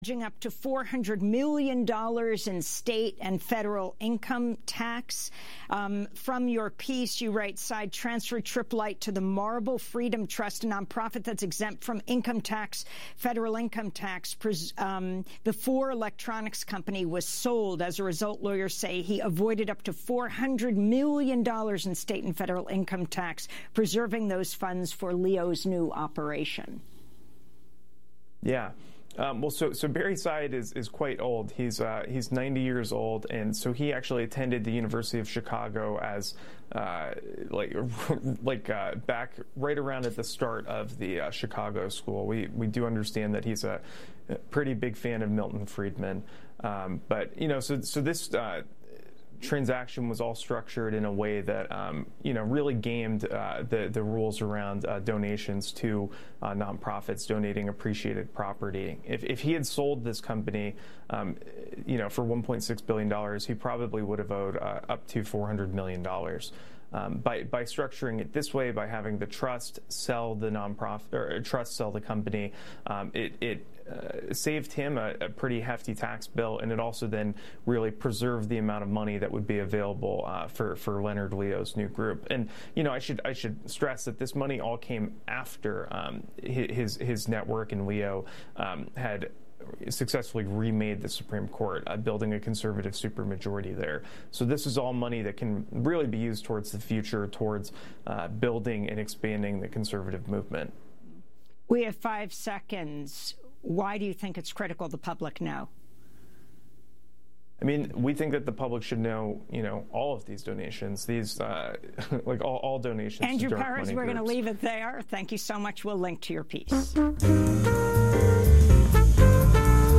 At the start of our show we bring you the headlines of the day.